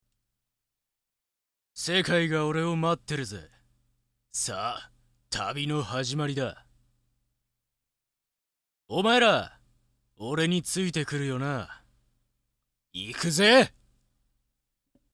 声質は中〜低
熱血・青年